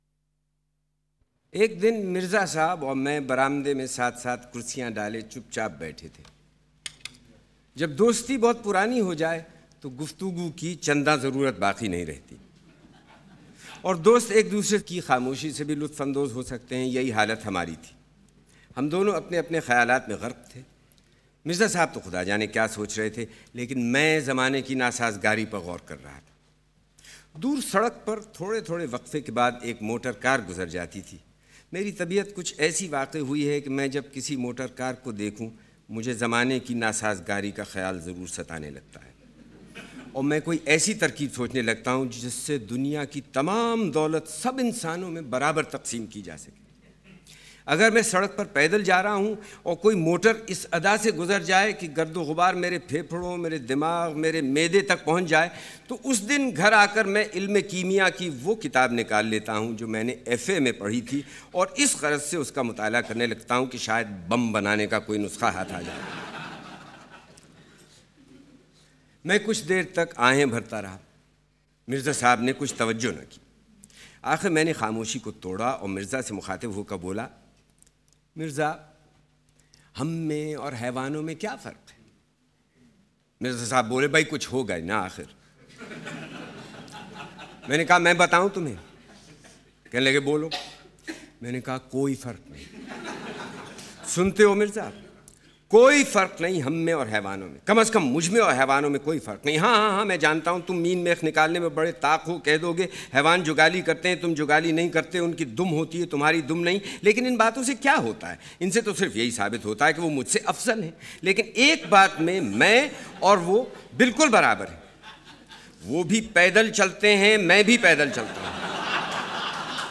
Zia Mohyeddin reads Urdu Adab (Prose and Poem)
It is story of a cycle that Patras buys from his friend Mirza, arguably the funniest story ever written in Urdu. Narrated and immortalized by voice of maestro Zia Mohyeddin